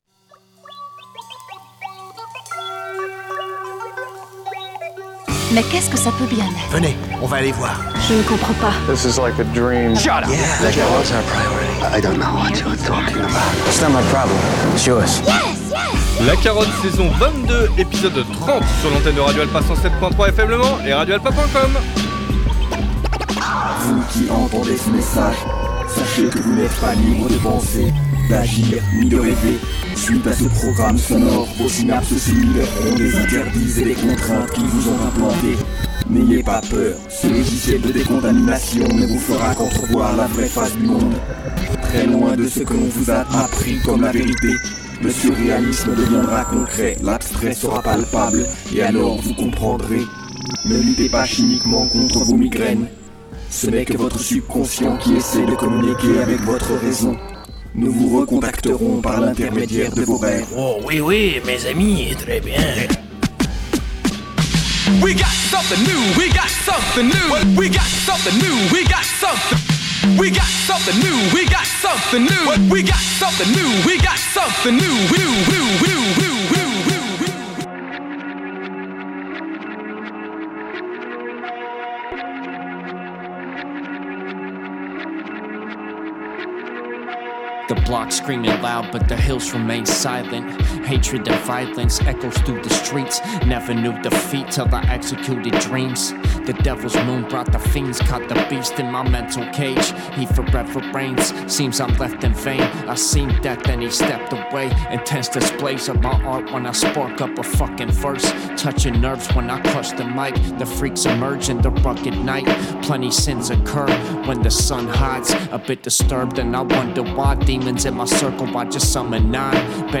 Une nouvelle fois le tirage au sort rapologique de nouveautés a eu lieu lors de cet épisode où toutes les oreilles curieuses vont finir triomphantes.